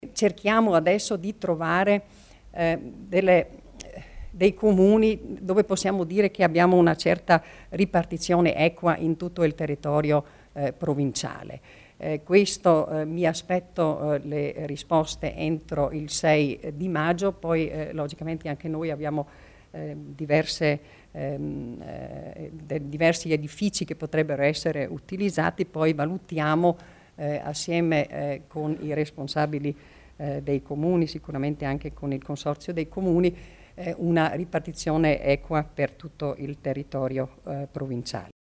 L'Assessore Martha Stocker illustra gli interventi a favore dei profughi